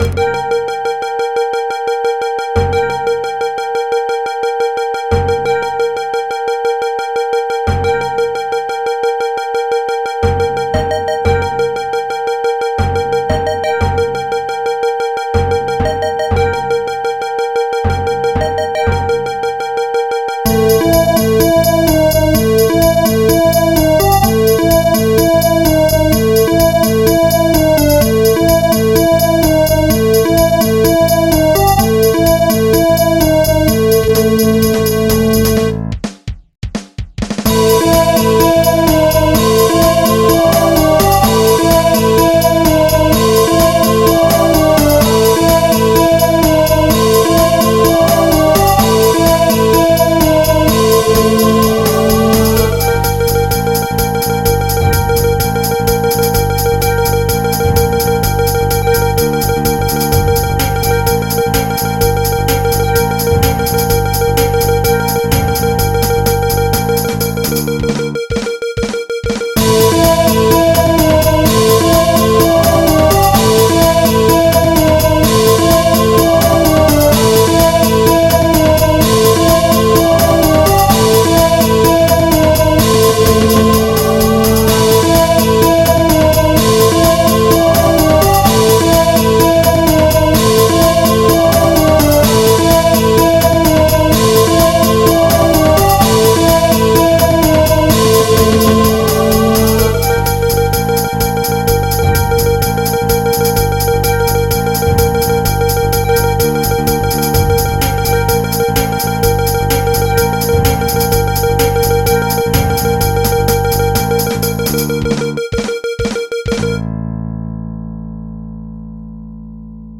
A Custom MIDI file